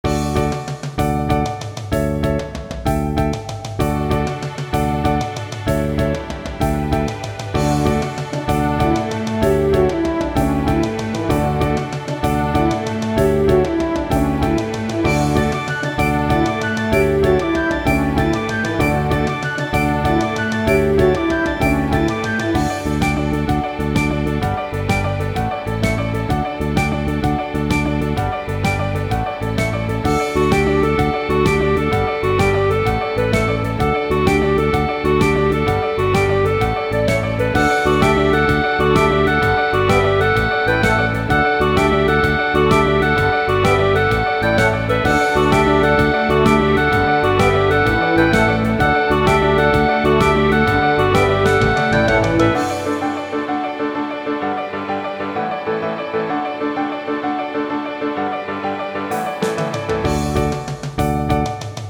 イメージ：達成 勝利   カテゴリ：RPG−街・村・日常